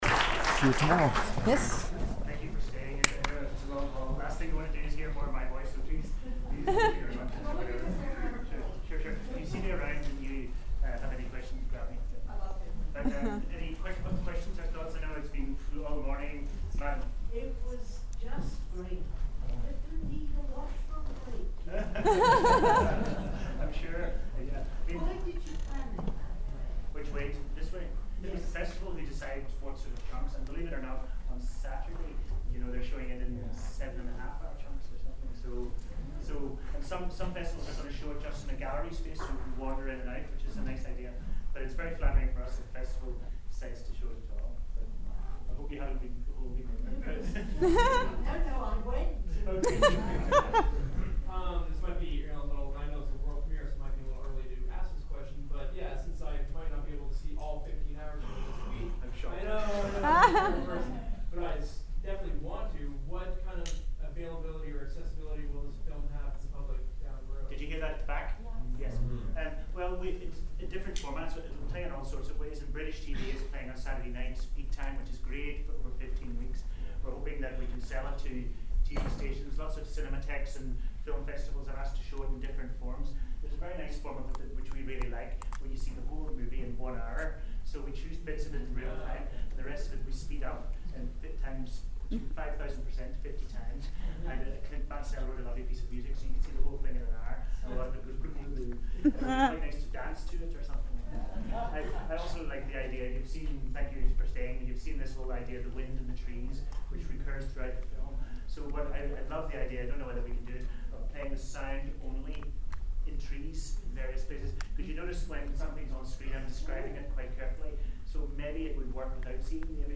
storyoffilm_1_qa.mp3